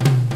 Southside Percussion (16).wav